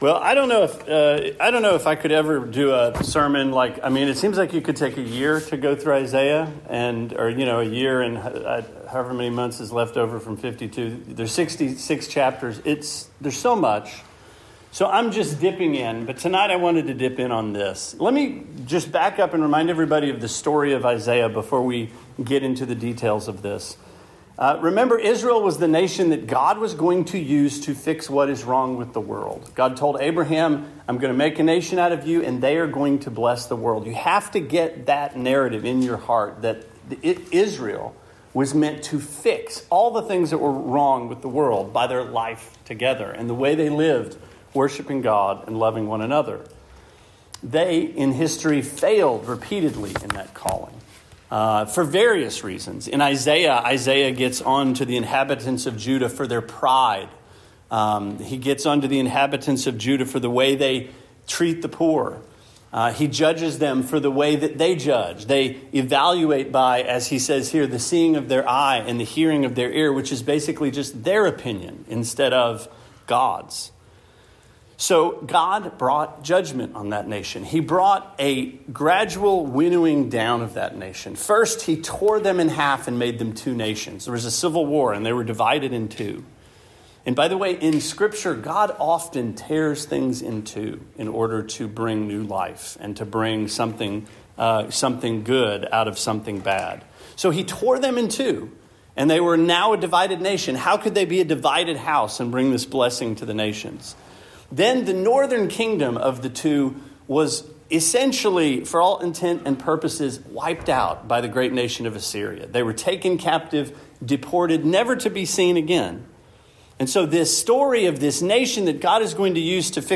Sermon 3/5: Stump, Branch, and Root